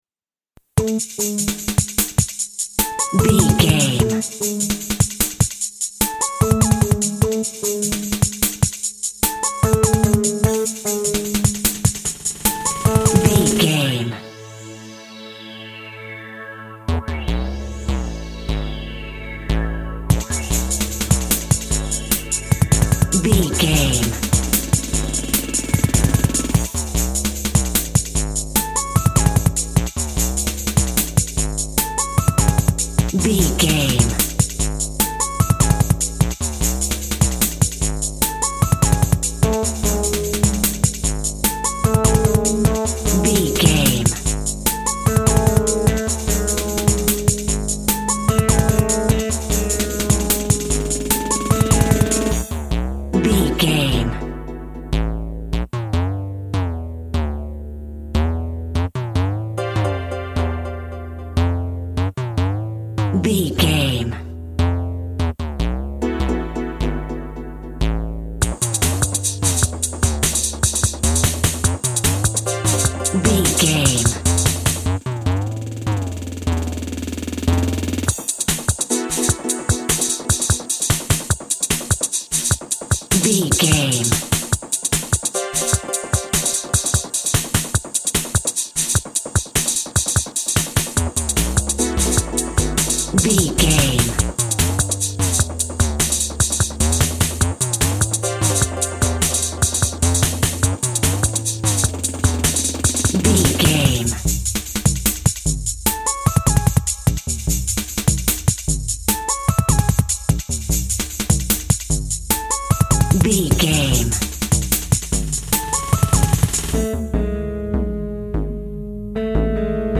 Ionian/Major
Fast
hypnotic
driving
energetic
frantic
bouncy
uplifting
drum machine
synthesiser
electric piano
drums
electronic
dance instrumentals
synth lead
synth bass